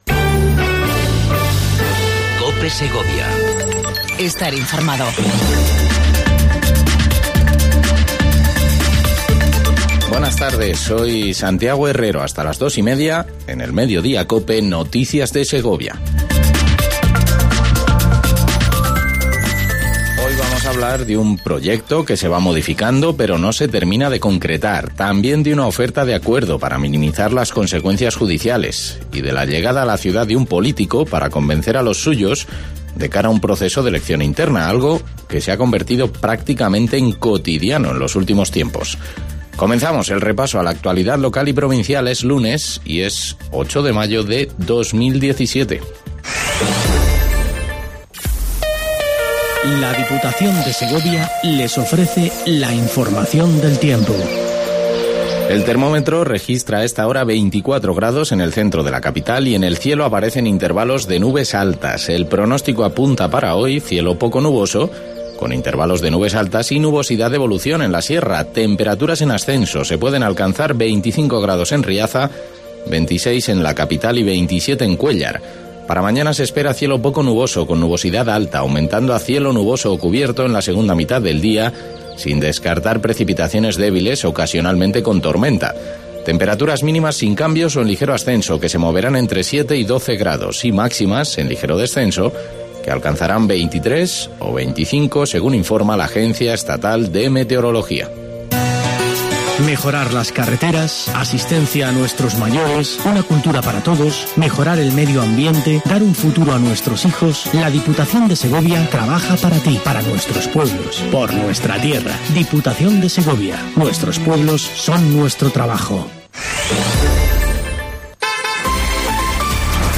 INFORMATIVO MEDIODIA COPE EN SEGOVIA 08 05 17